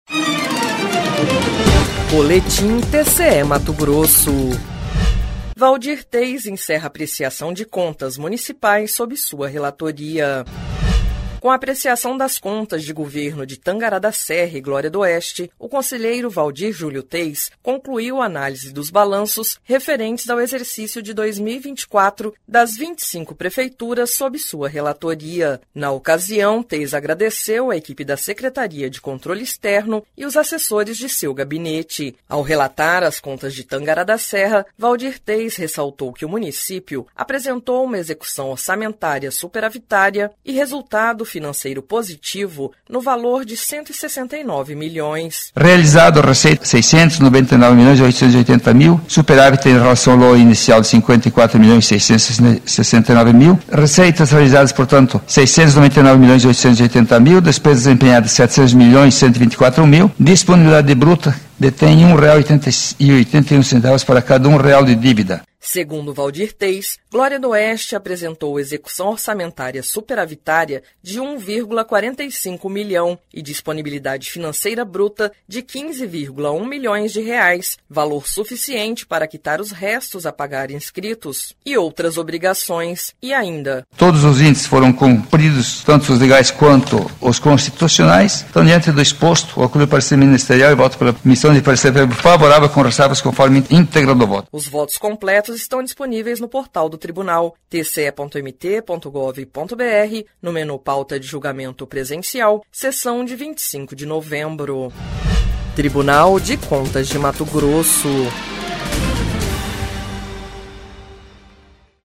Sonora: Waldir Júlio Teis – conselheiro do TCE-MT